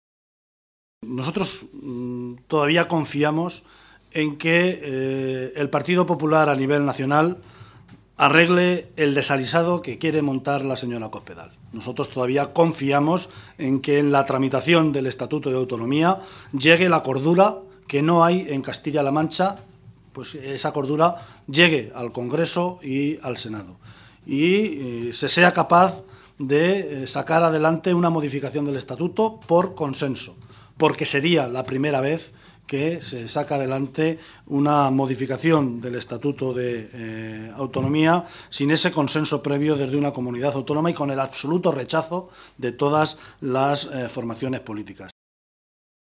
José Luis Martínez Guijarro, portavoz del Grupo Parlametario Socialista
Cortes de audio de la rueda de prensa